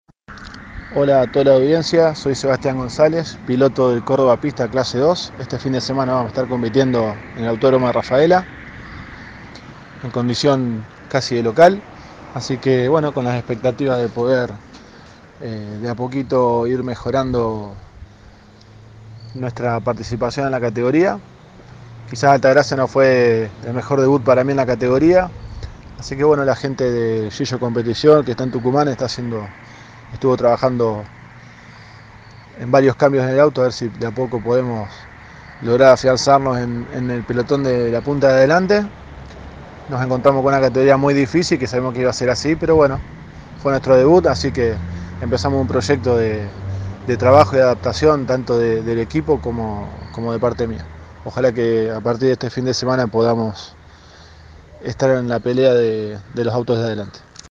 Los pilotos santafesinos realizaron declaraciones antes de la quinta fecha.